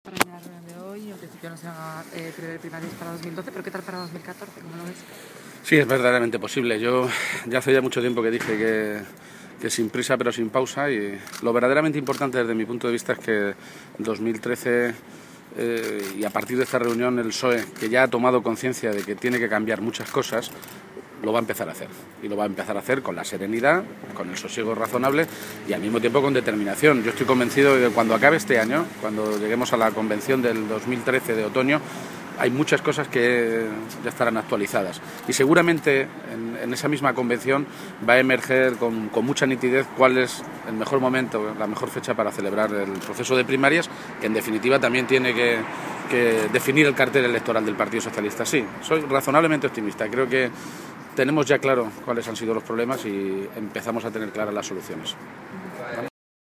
García-Page en el Comité Federal del PSOE
Cortes de audio de la rueda de prensa